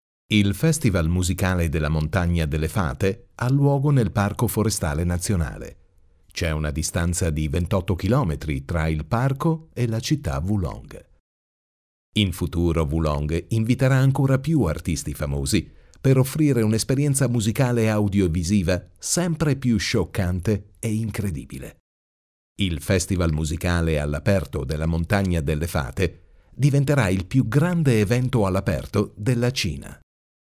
意大利语样音试听下载
意大利语配音员（男1） 意大利语配音员（男2） 意大利语配音员（男3） 意大利语配音员（男4）